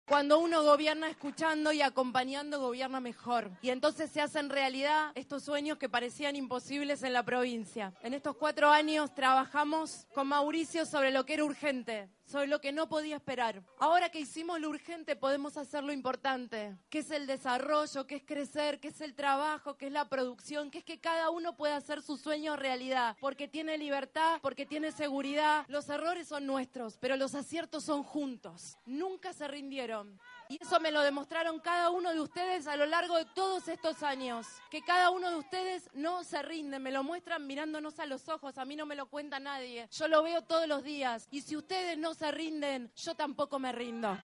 VIDAL ACOMPAÑÓ A MACRI EN LA INAUGURACIÓN DEL METROBUS FLORENCIO VARELA
La gobernadora de la provincia de Buenos Aires, María Eugenia Vidal, acompañó hoy al presidente de la Nación, Mauricio Macri, en la inauguración del nuevo Metrobus Florencio Varela.